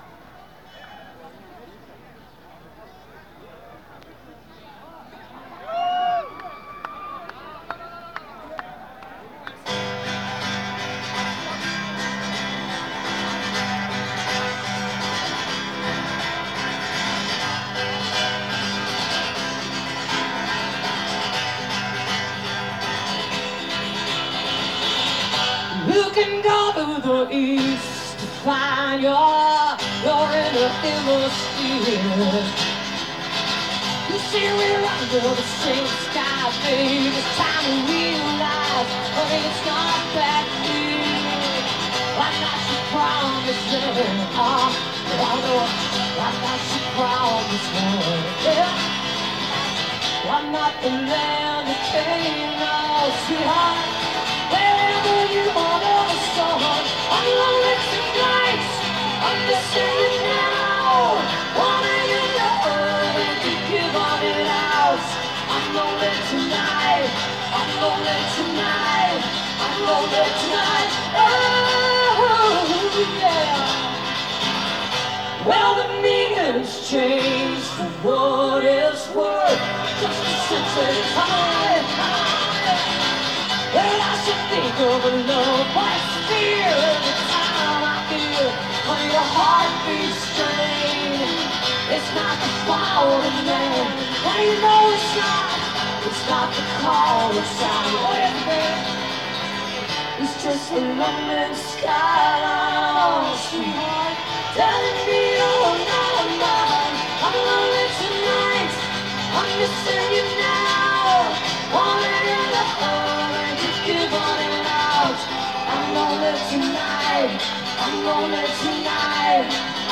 (acoustic duo show)